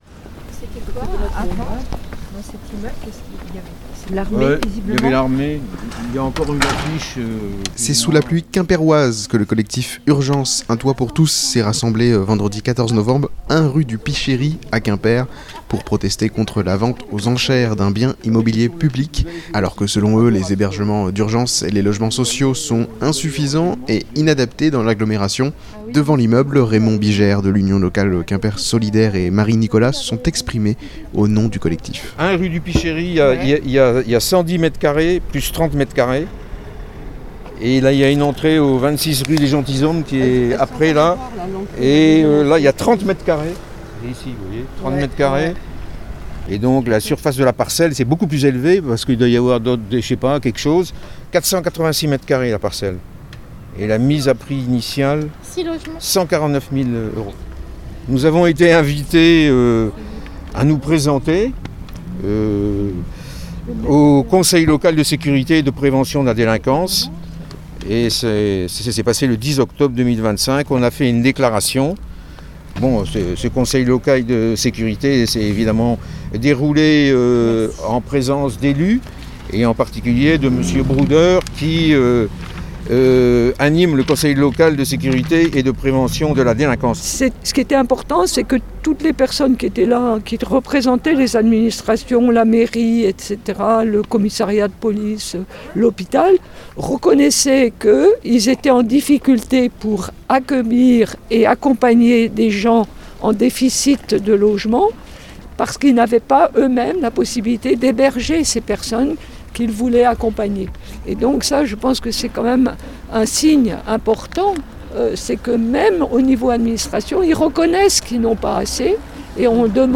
Reportage au 1 Rue du Pichery à Quimper.